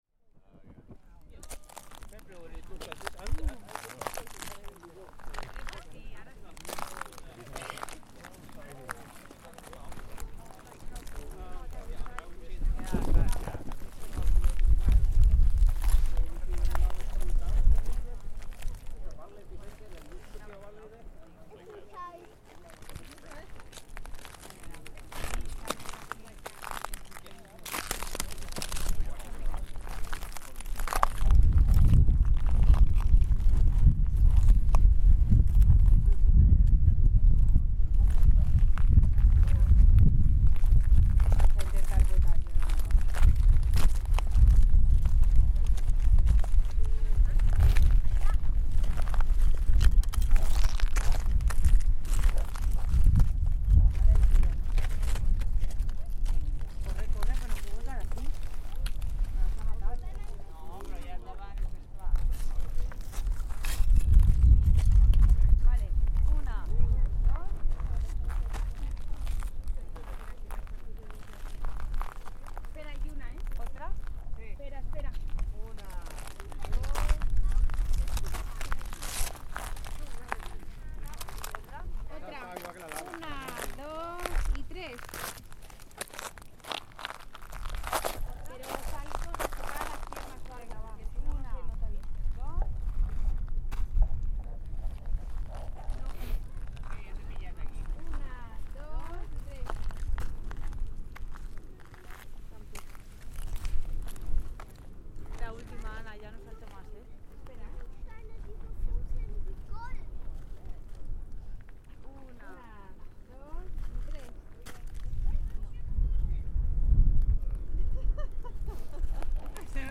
Walking on freshly-cooled lava
Walking on recently-cooled lava at the site of the Geldingadalir Volcano, Iceland, August 2021.